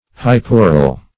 Definition of hypural.
Search Result for " hypural" : The Collaborative International Dictionary of English v.0.48: Hypural \Hy*pu"ral\, a. [Pref. hypo- + Gr.